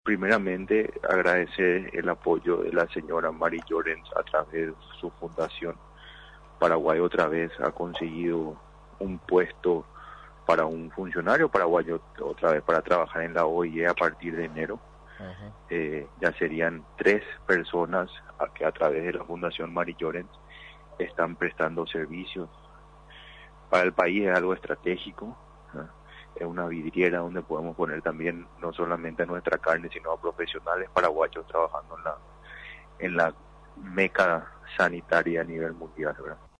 Paraguay nuevamente forma parte del Consejo Mundial de Sanidad Animal (OIE), informó este martes el presidente del Servicio de Calidad y Salud Animal (SENACSA) José Martin.